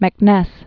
(mĕk-nĕs)